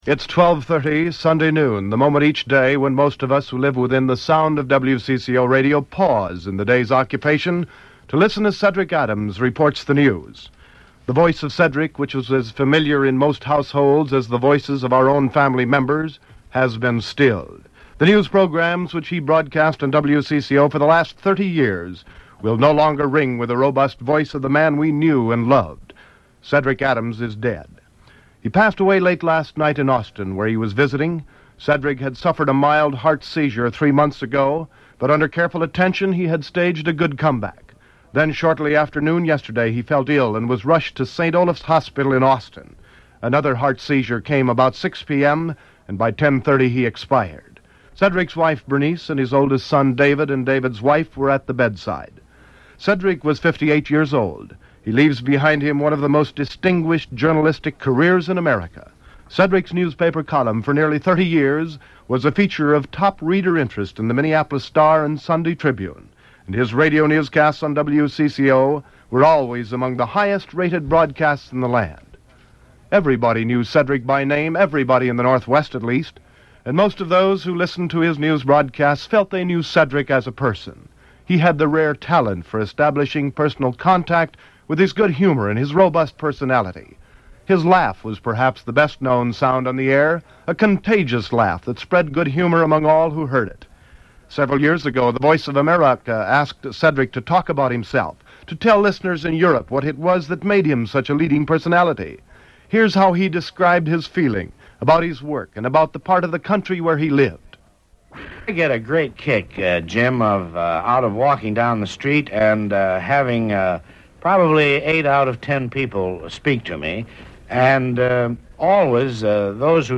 Explore the legacy of Cedric Adams, WCCO Radio personality, through airchecks recordings from his iconic broadcasting career.